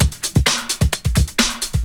Index of /90_sSampleCDs/Ueberschall - Techno Trance Essentials/02-29 DRUMLOOPS/TE20-24.LOOP-ADDON+HIHAT/TE20.LOOP-ADDON2